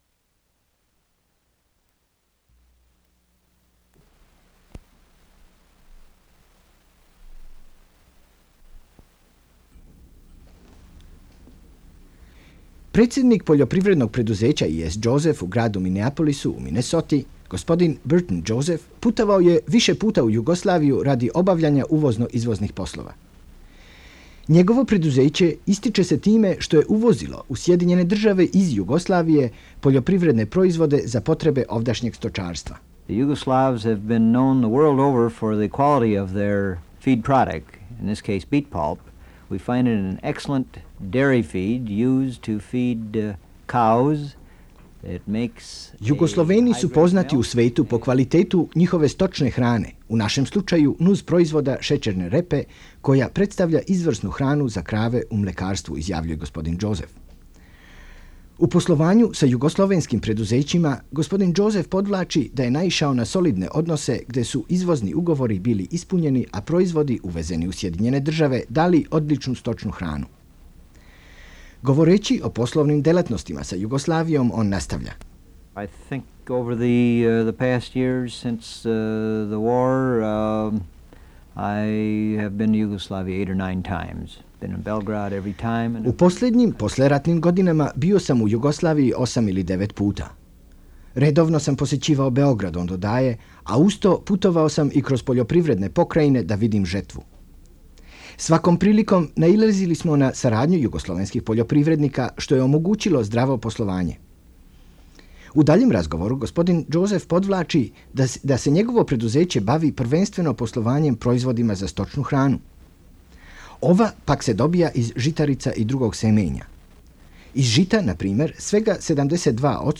Serbo-Croatian dubbing